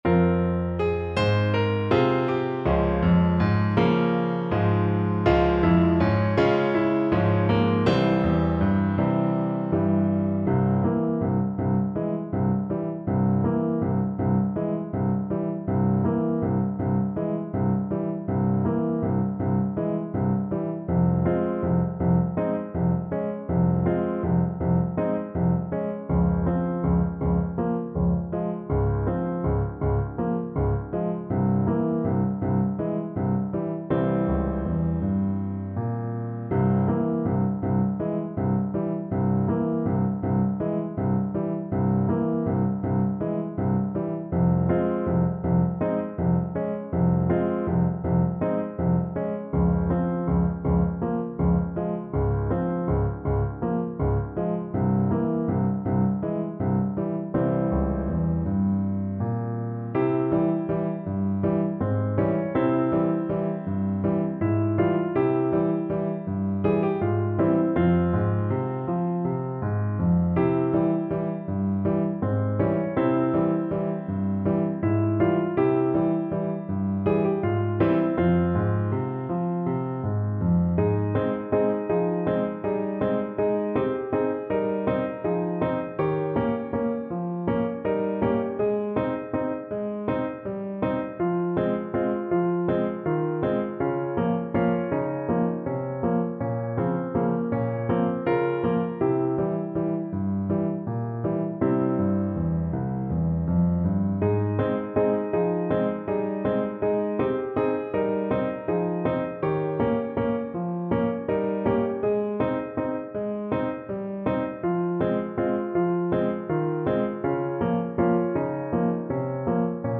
World Europe Serbia Majko Majko
Alto Saxophone
F minor (Sounding Pitch) D minor (Alto Saxophone in Eb) (View more F minor Music for Saxophone )
7/8 (View more 7/8 Music)
Moderato = 112
Eastern European for Alto Saxophone